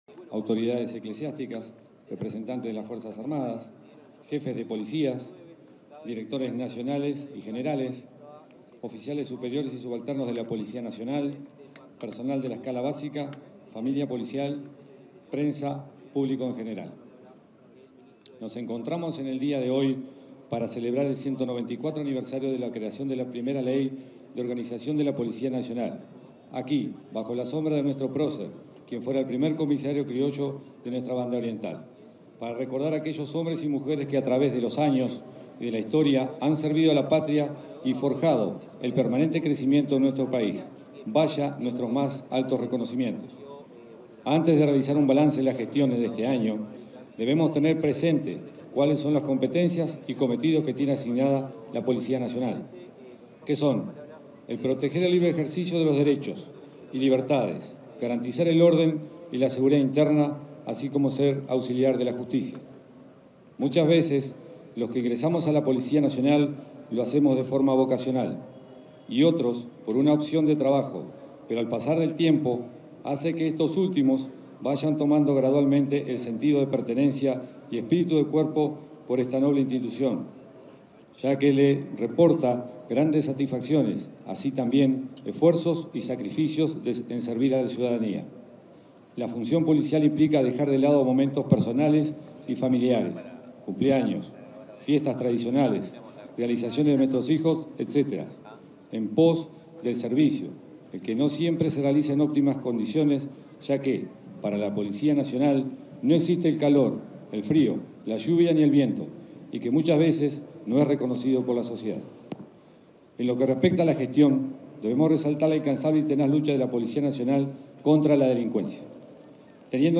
Palabras del director de la Policía Nacional, José Azambuya
En el marco del acto oficial por el 194.° aniversario de la Policía Nacional, este 21 de diciembre, se expresó su director, José Azambuya.